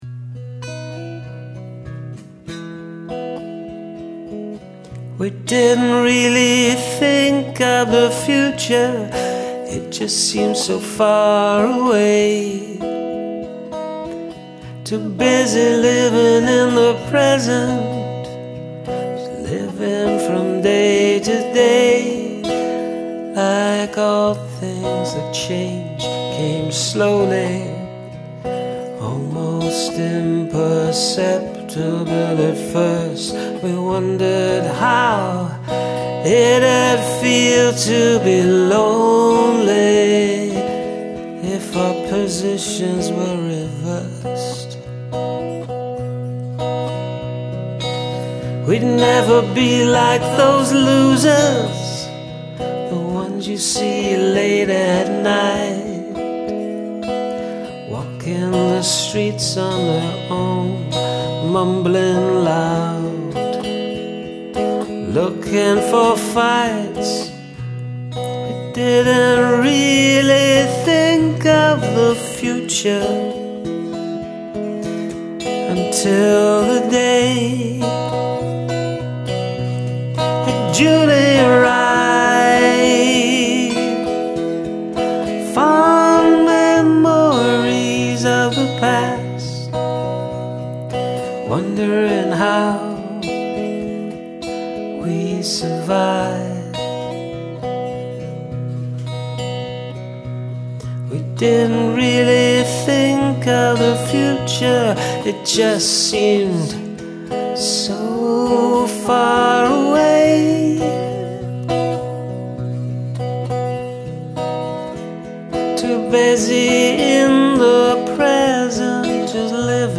Now I hear some slide gtr on this track. Great harmonies